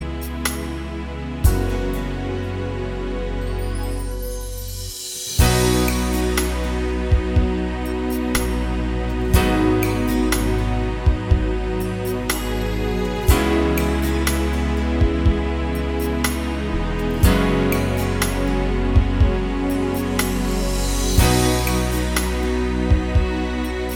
Duet Version Pop (2000s) 4:08 Buy £1.50